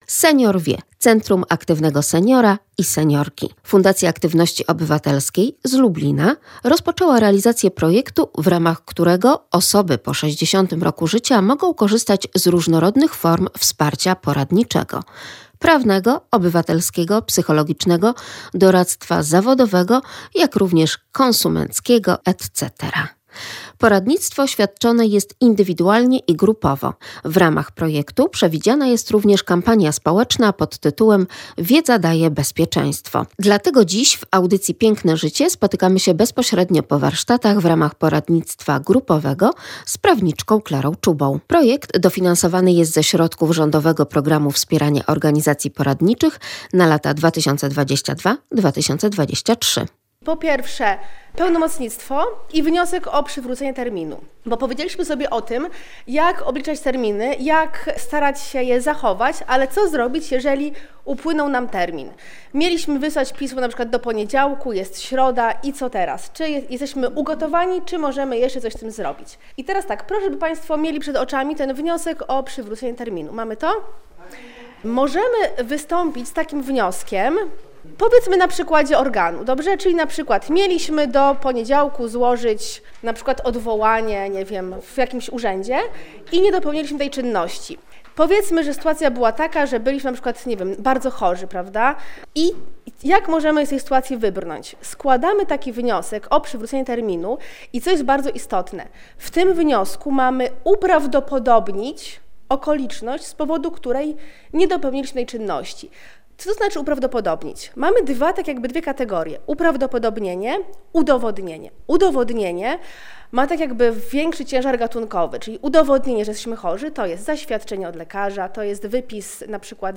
prawniczka prowadząca szkolenie dla seniorów